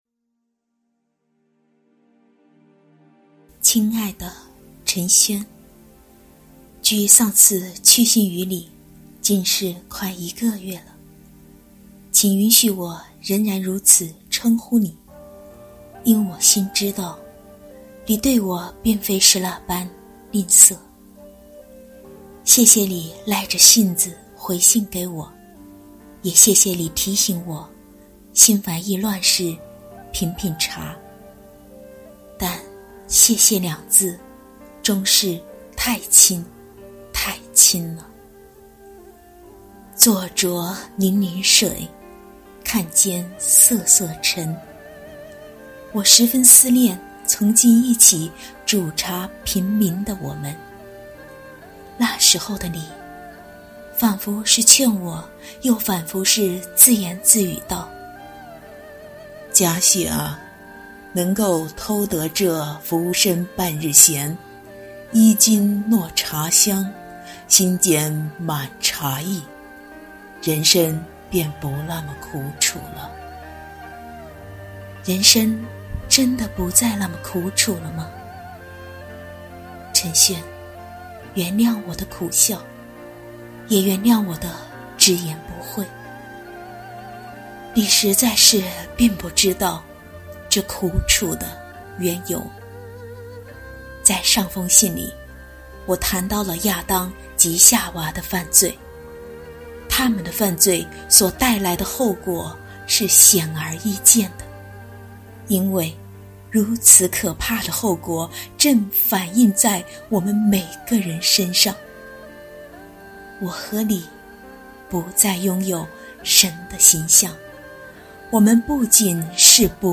有声书《神的应许》▶神赐下应许(二)◀世人饮茶多自欺